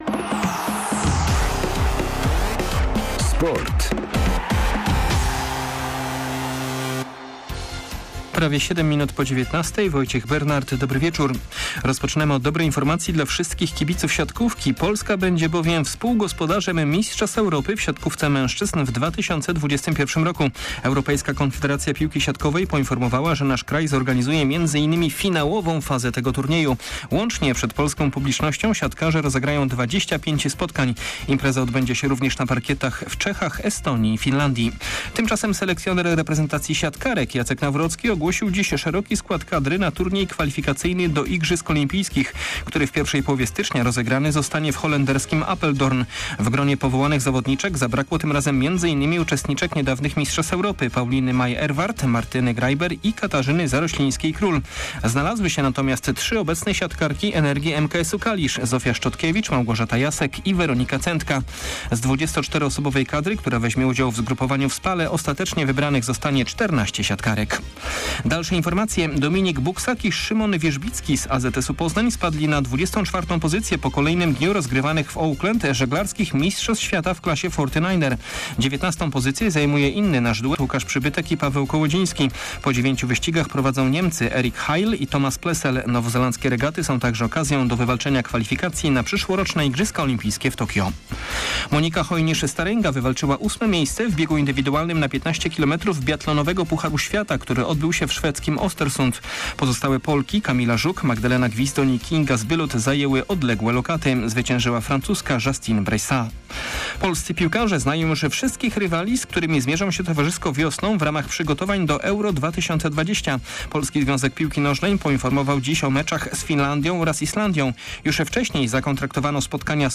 05.12. SERWIS SPORTOWY GODZ. 19:05